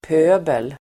Uttal: [p'ö:bel]